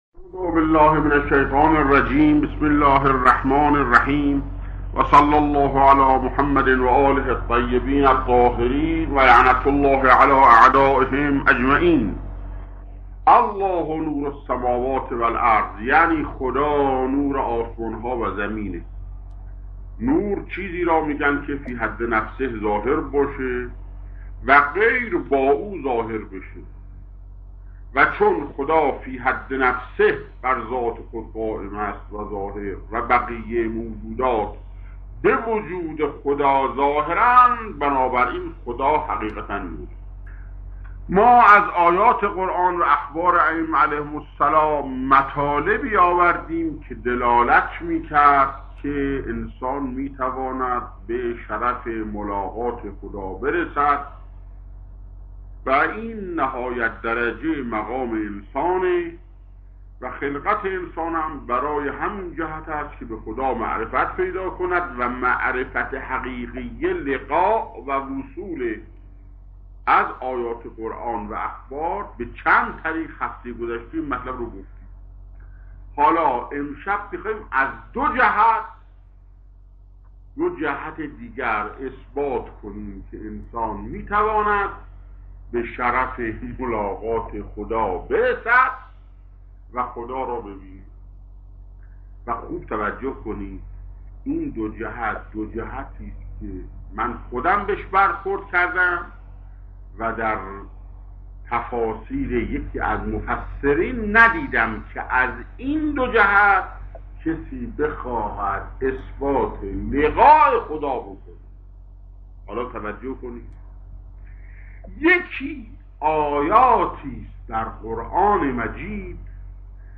سخنرانی‌های